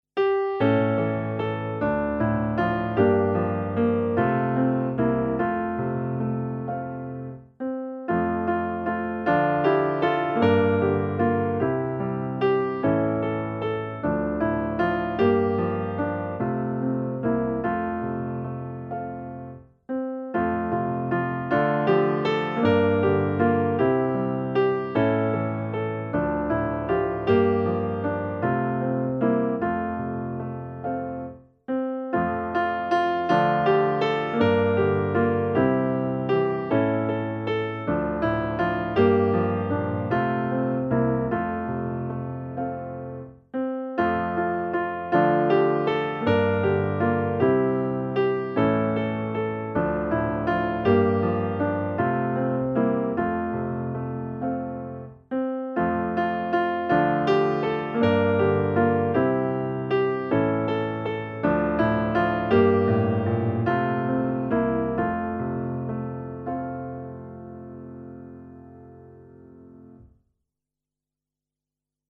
musikbakgrund
Musikbakgrund Psalm